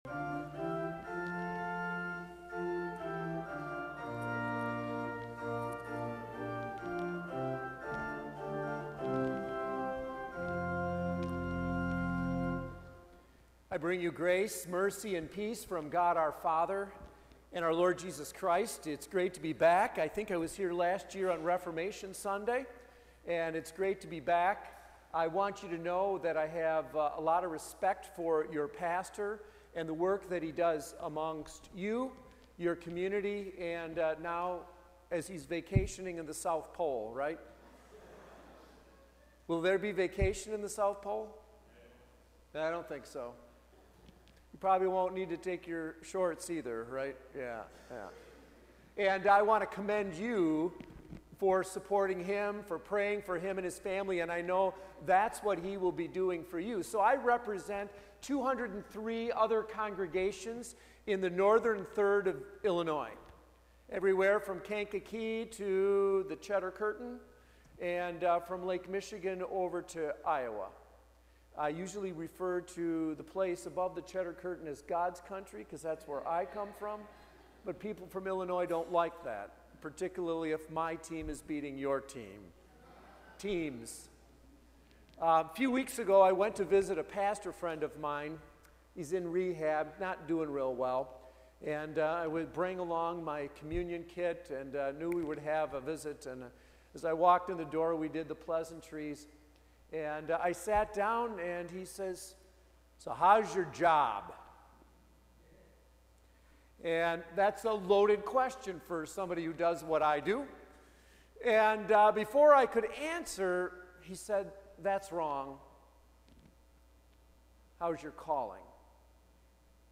Current-sermon-2.mp3